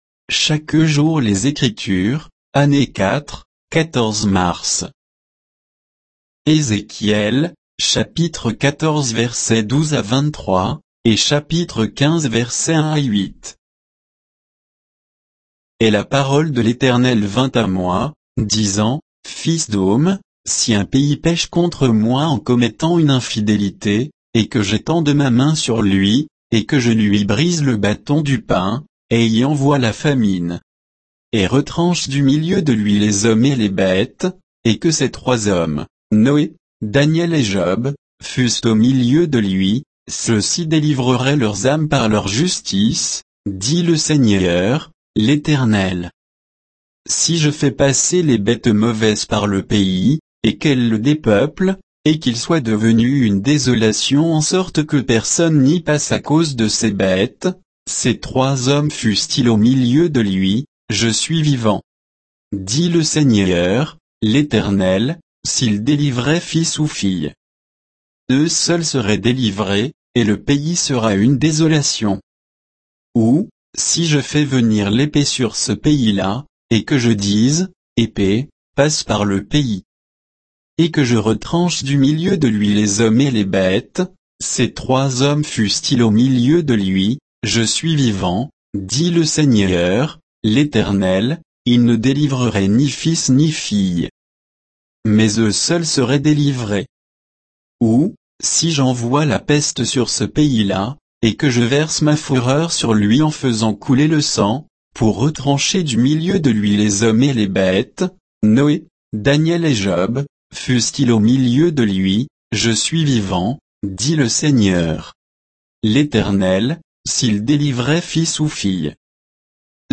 Méditation quoditienne de Chaque jour les Écritures sur Ézéchiel 14, 12 à 15, 8